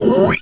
pgs/Assets/Audio/Animal_Impersonations/pig_2_hog_single_05.wav at master
pig_2_hog_single_05.wav